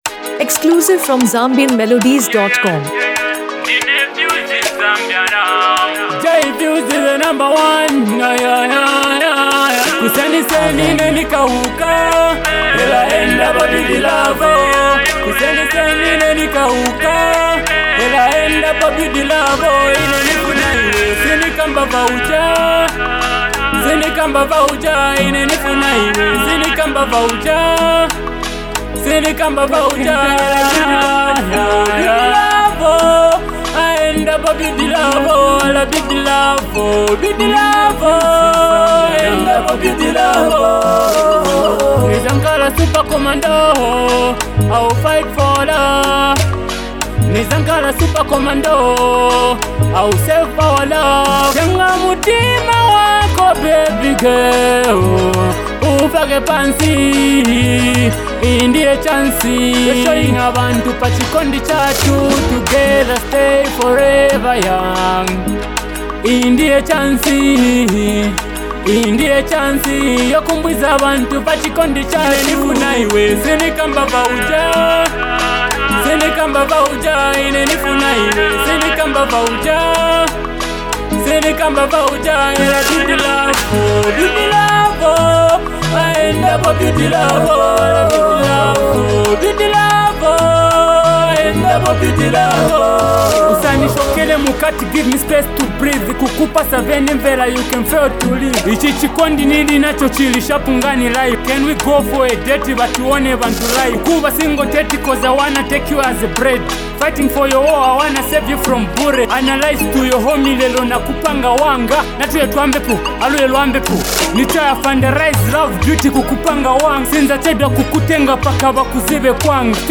powerful and uplifting track
Through soulful melodies and catchy Afro-fusion rhythms